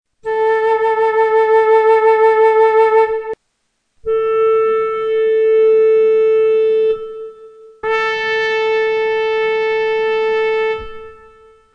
Consideriamo, ad esempio, un identico suono - il la 440 Hz - suonato da un flauto, da una tromba, da un clarinetto.
Il la viene eseguito da una tromba, un flauto, un clarinetto, ma non in quest'ordine.
timbri.mp3